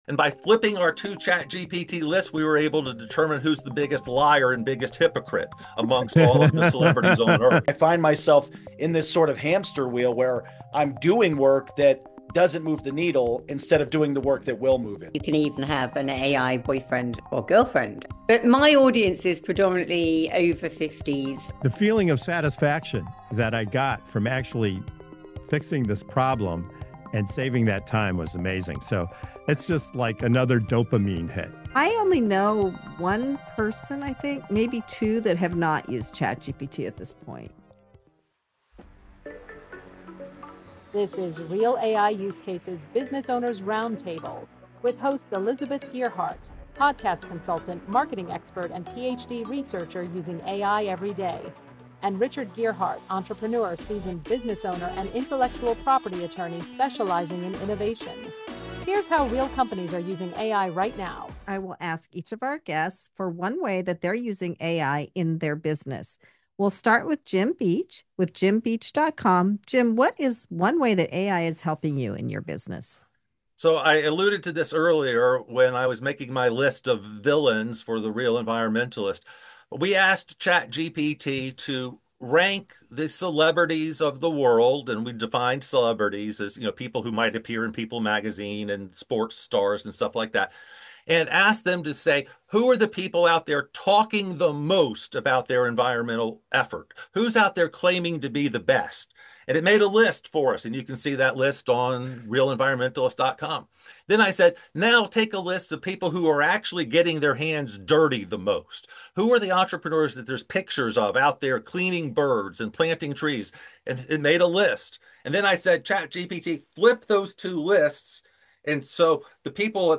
Business Owners Roundtable Customizing LLMs using no-code tools & using AI for automation